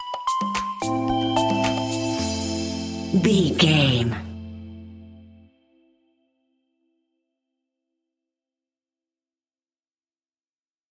Aeolian/Minor
groovy
peaceful
smooth
drum machine
synthesiser
house
electro
synth leads
synth bass